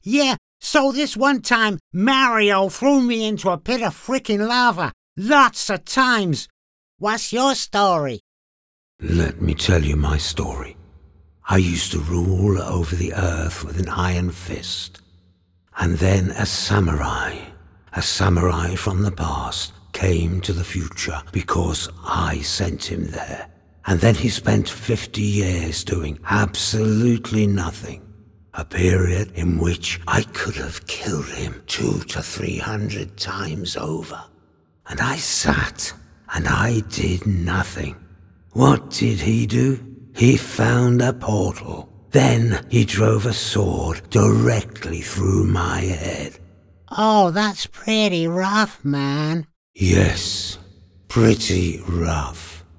I have the flexibility to vary colour, tone, pitch, and shape for character voices.
Video Games
Games Animation Interactive
Words that describe my voice are Neutral British, Clear, Expressive.